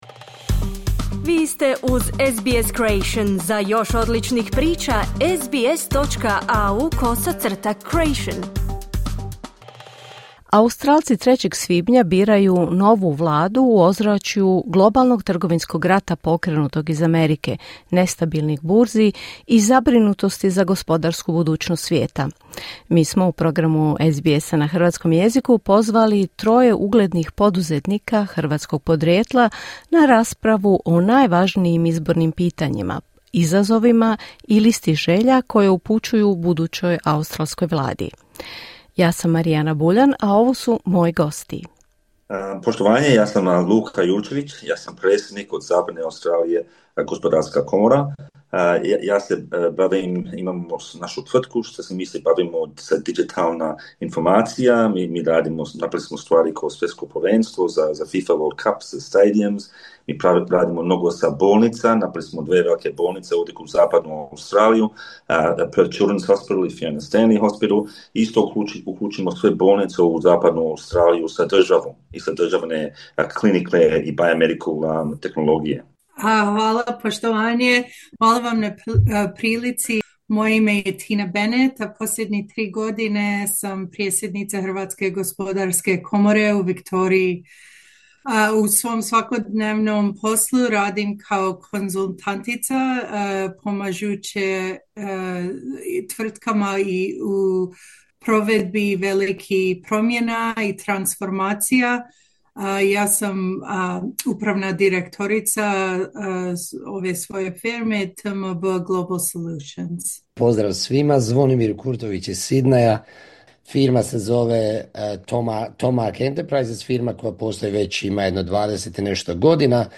Australci 3. svibnja biraju novu vladu u ozračju globalnog trgovinskog rata pokrenutog iz Amerike, nestabilnih burzi i zabrinutosti za gospodarsku budućnost svijeta. Pozvali smo troje uglednih poduzetnika hrvatskog podrijetla na raspravu o najvažnijim izbornim pitanjima, izazovima poslovanja i listi želja koju upućuju budućoj australskoj vladi.